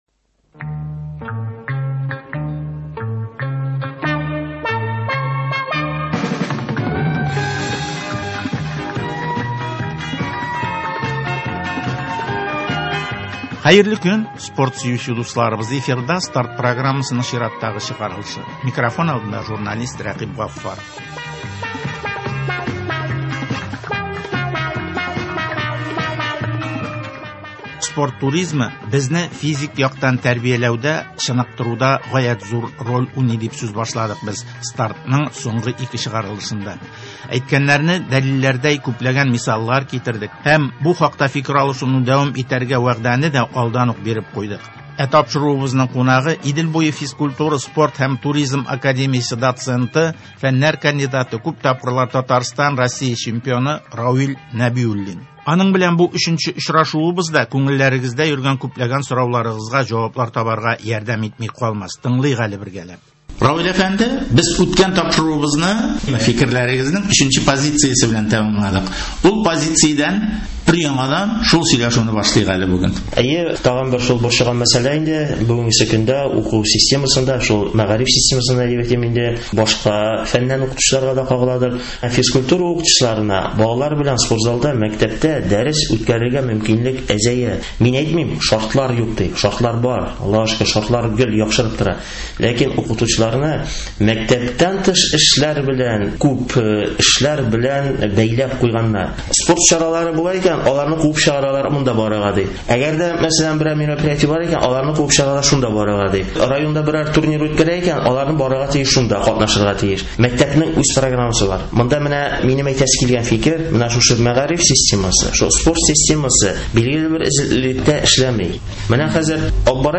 Казан – Россиянең спорт башкаласы, авылда спортның үсеше, дөнья күләмендәге чемпионатларга әзерләнү, районнар масштабындагы ярышларны үткәрү – әлеге һәм башка темалар хакында спортчылар, җәмәгать эшлеклеләре һәм спорт өлкәсендәге белгечләр белән әңгәмәләр.